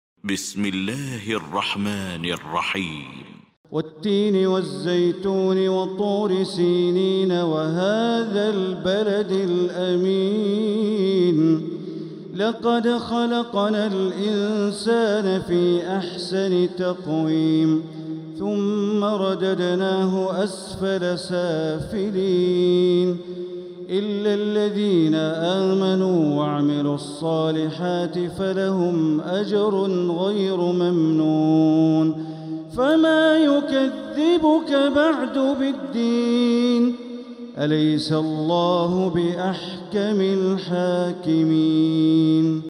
المكان: المسجد الحرام الشيخ: معالي الشيخ أ.د. بندر بليلة معالي الشيخ أ.د. بندر بليلة التين